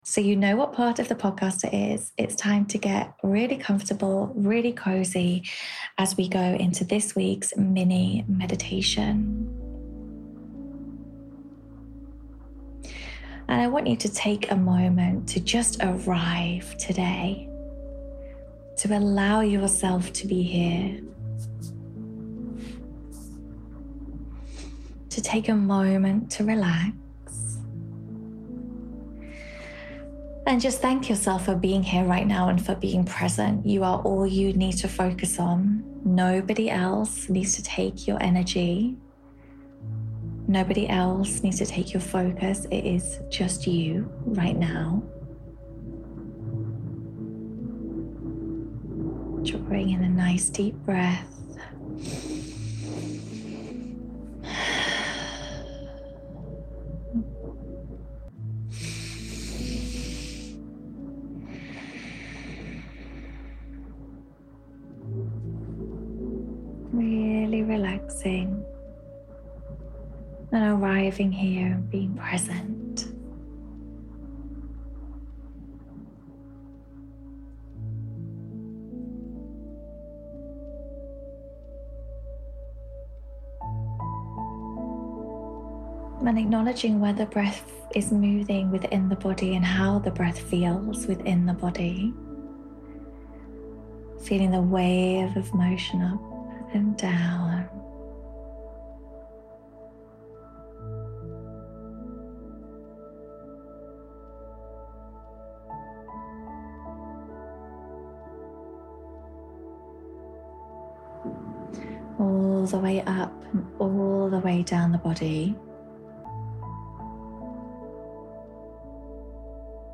Todays Meditation is all about feeling secure in yourself and your body.